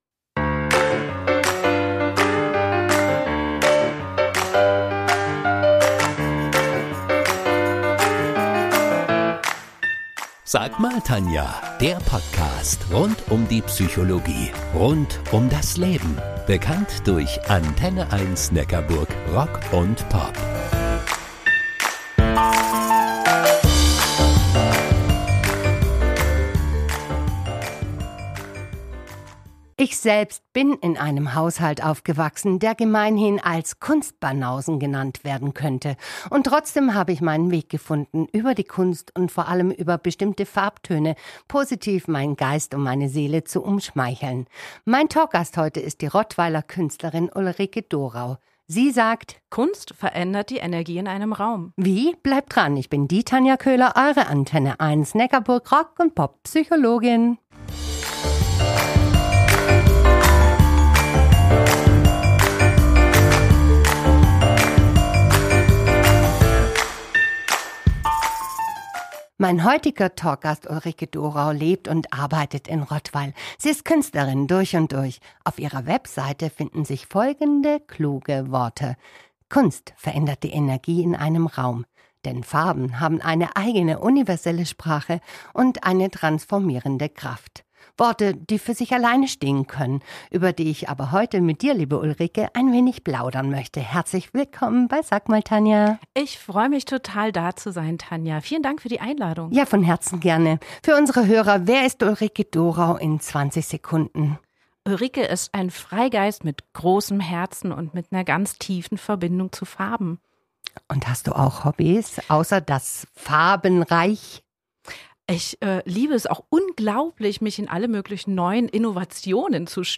Im Gespräch
Podcast-Episode ist ein Mitschnitt der Original-Redebeiträge meiner